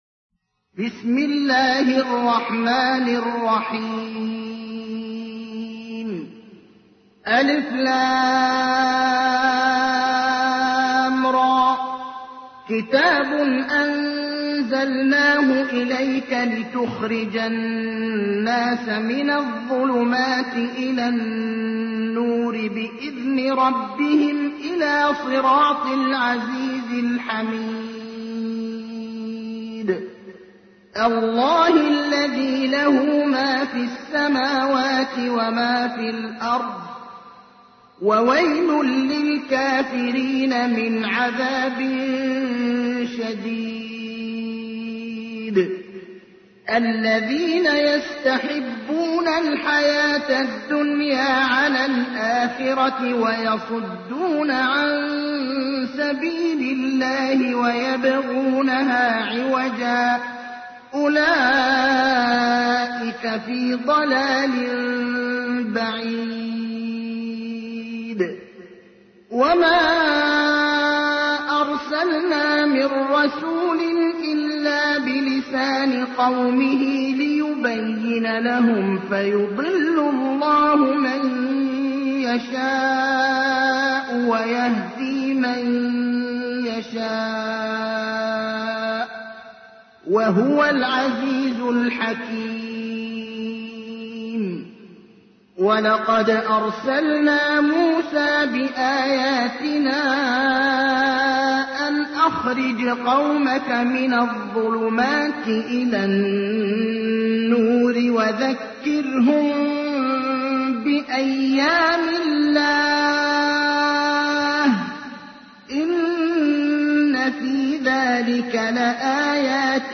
تحميل : 14. سورة إبراهيم / القارئ ابراهيم الأخضر / القرآن الكريم / موقع يا حسين